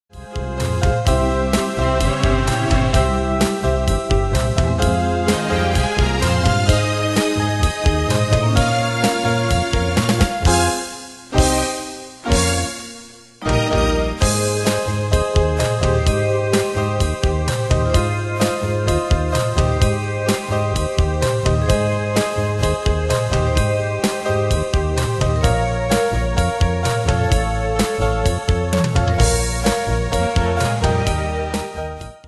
Style: Retro Année/Year: 1975 Tempo: 128 Durée/Time: 3.22
Danse/Dance: ChaCha Cat Id.
Pro Backing Tracks